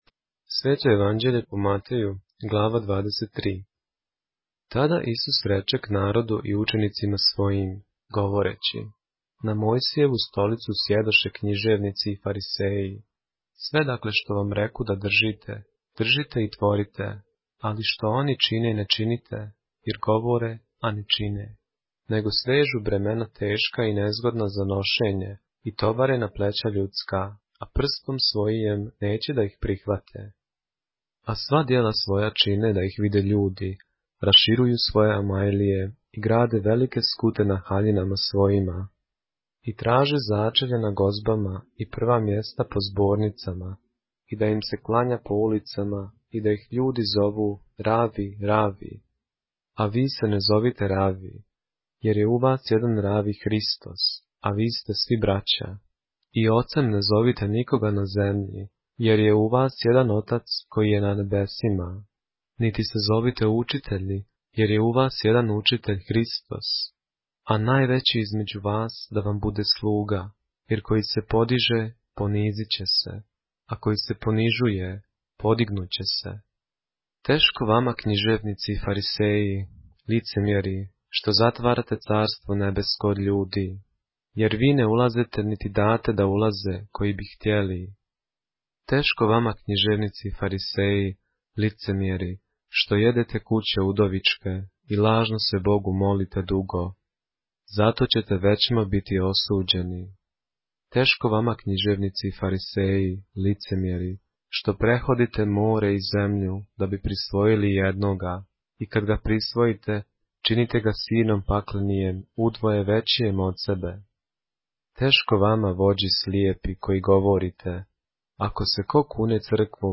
поглавље српске Библије - са аудио нарације - Matthew, chapter 23 of the Holy Bible in the Serbian language